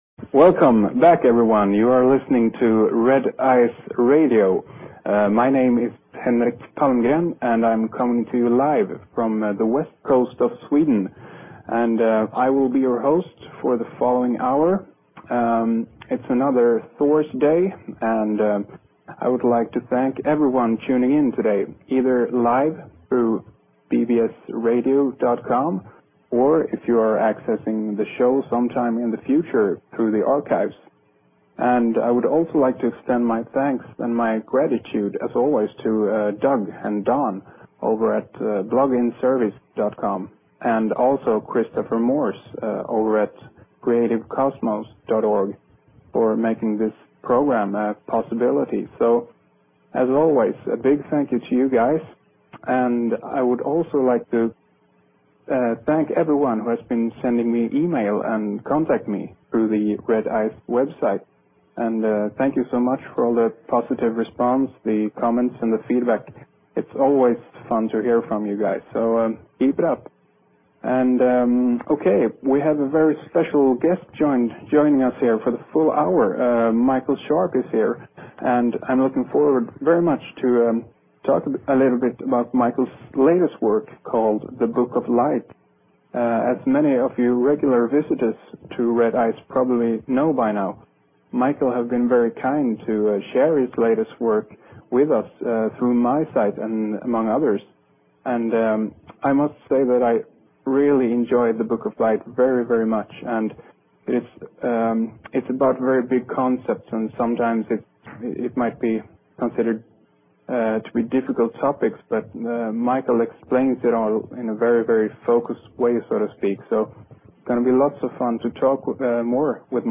Talk Show Episode, Audio Podcast, Mind_Goal and Courtesy of BBS Radio on , show guests , about , categorized as